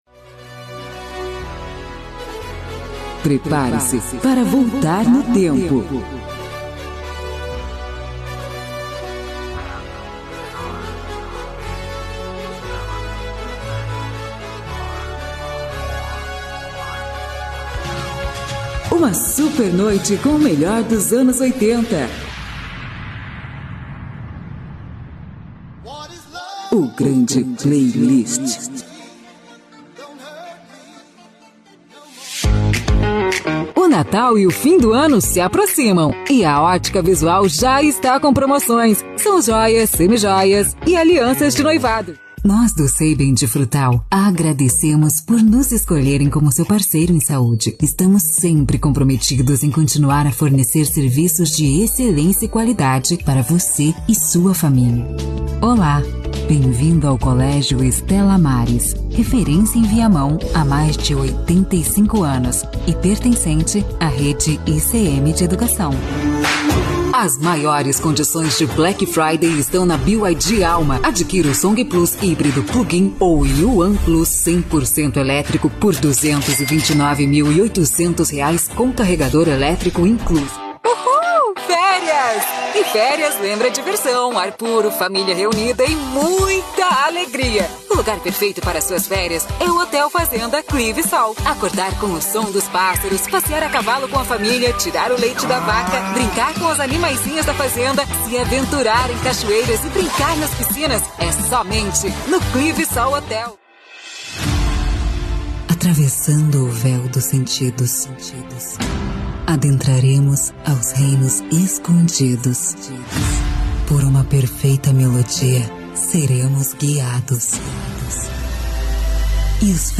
Spot Comercial
Animada